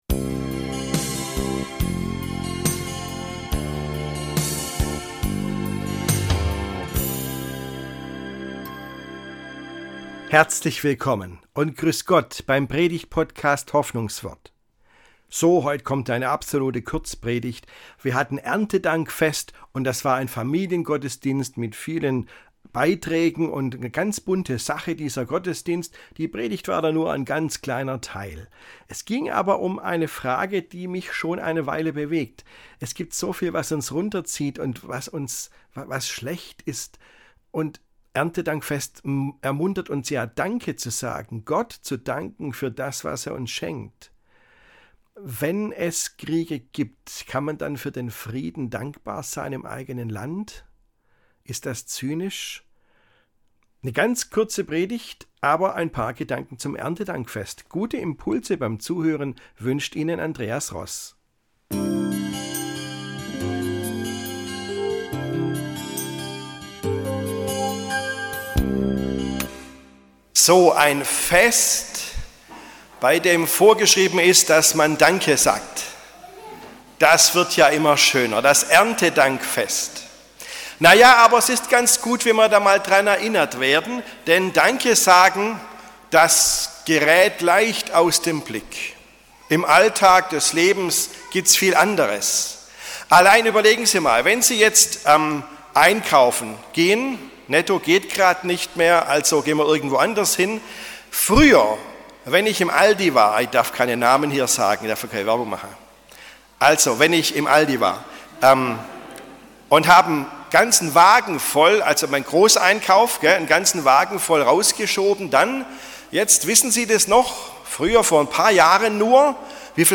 Hoffnungswort - Predigten